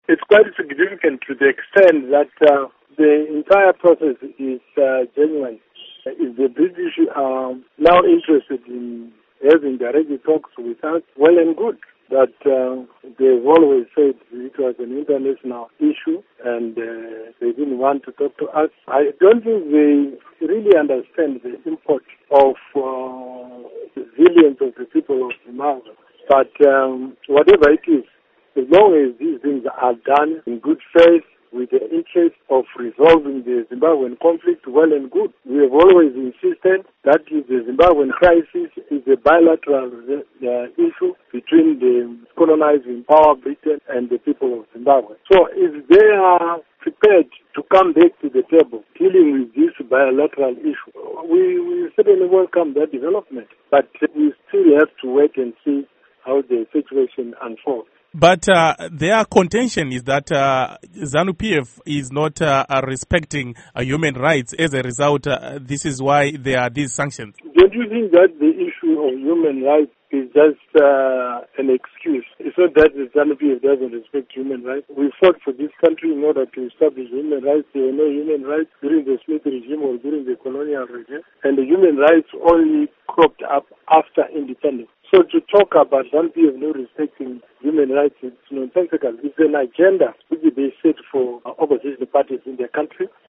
Interview With Rugare Gumbo